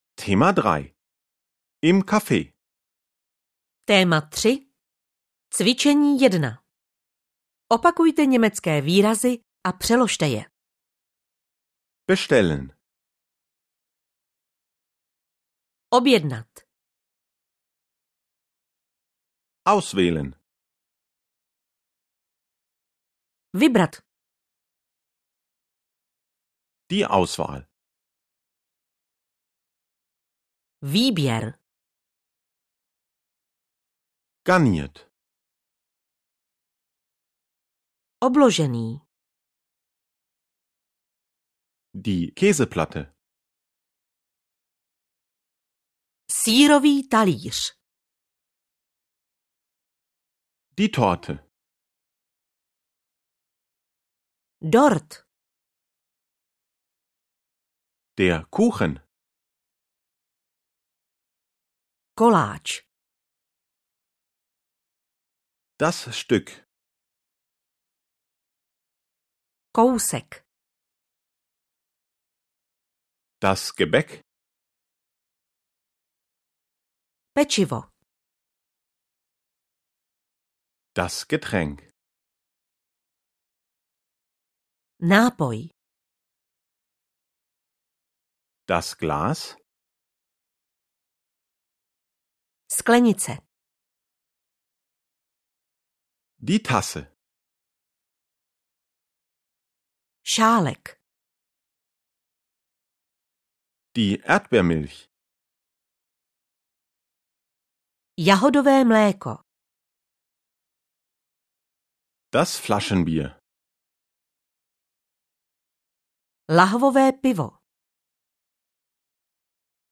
Němčina nejen do auta audiokniha
Ukázka z knihy